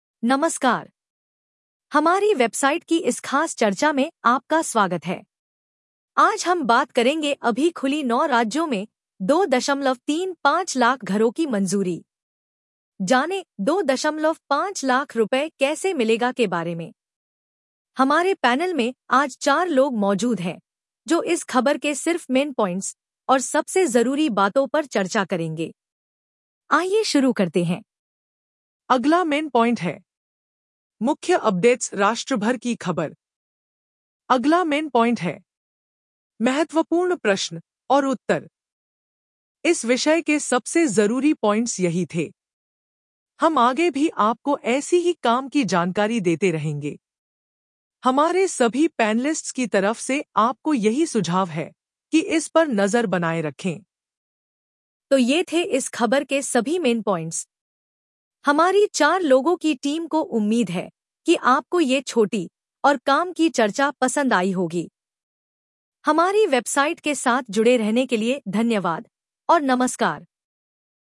🎧 इस खबर को सुनें (AI Audio):